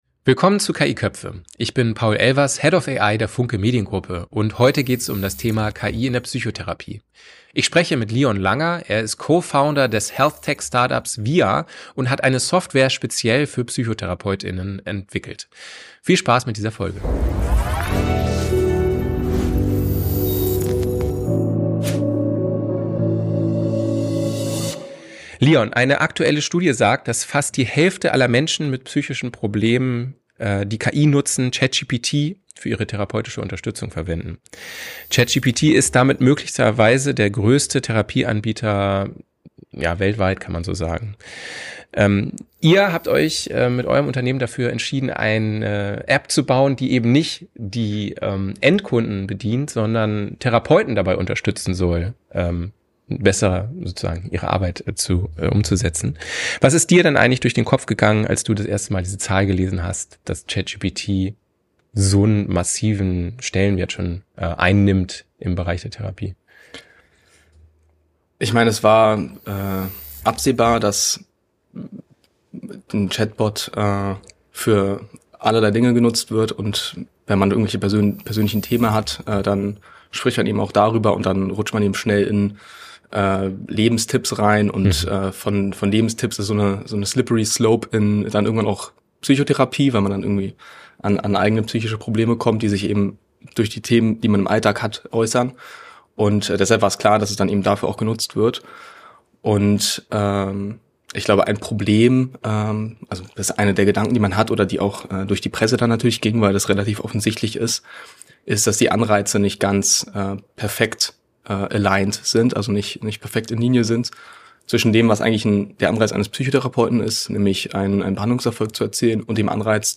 Ein Gespräch über Regulierung, Datenschutz, therapeutische Allianz und die Frage, wie sich der Beruf von Psychotherapeut:innen durch KI wirklich verändern wird.